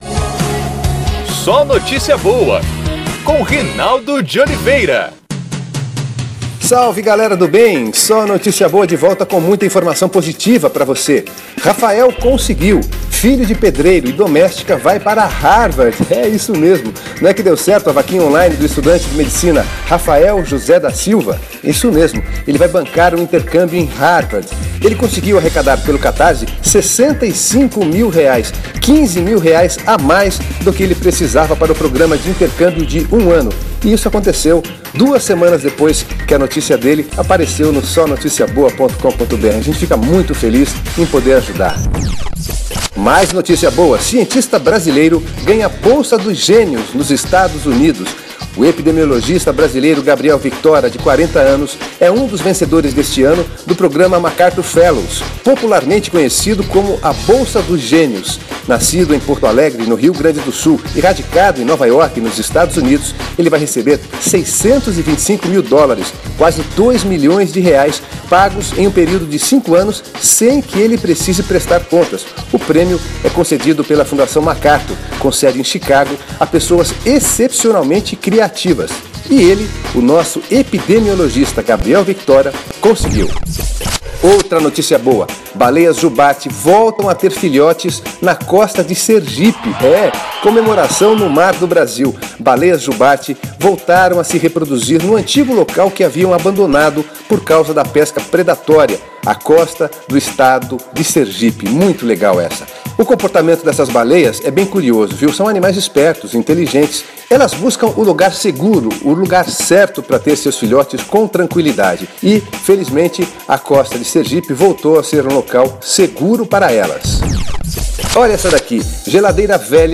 É o PodCast SóNotíciaBoa, exibido diariamente em pílulas na Rádio Federal, de Brasília.